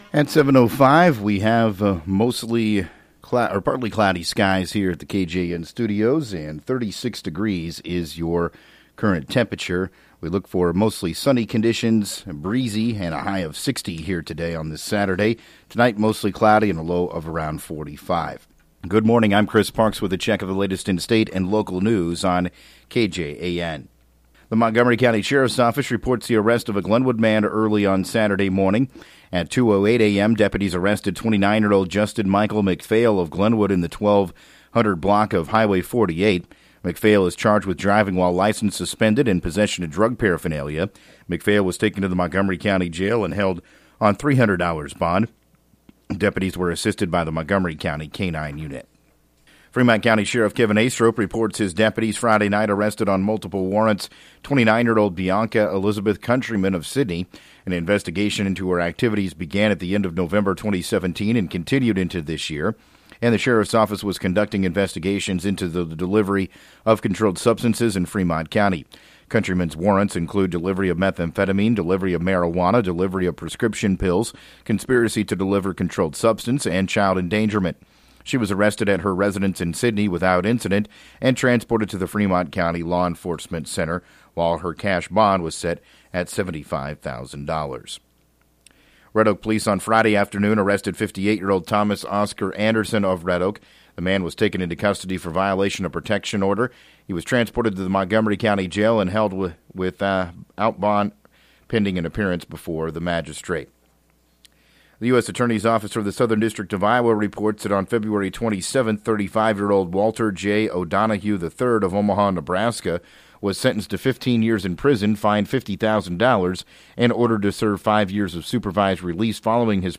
7AM Newscast 03/03/2018